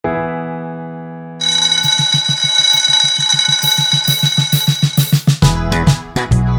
With Intro Count And No Backing Vocals